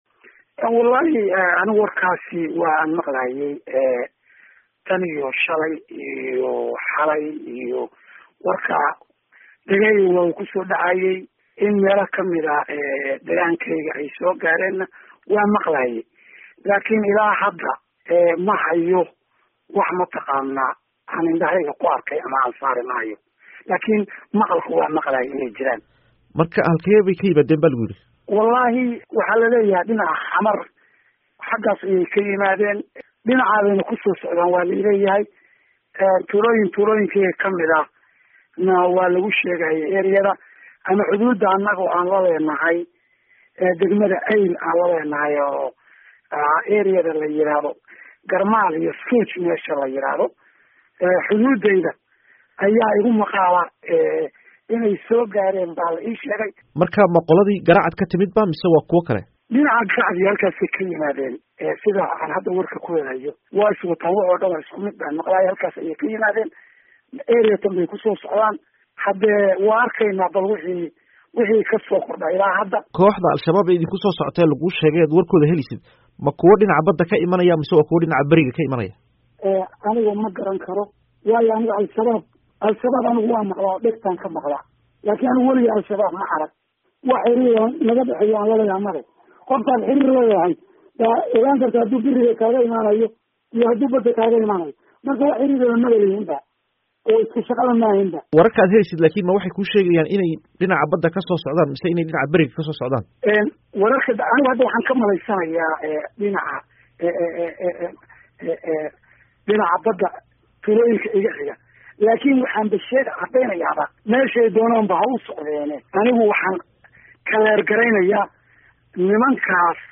Wareysi: Guddoomiyaha Bandar Bayla